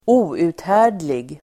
Uttal: [²'o:u:thä:r_dlig]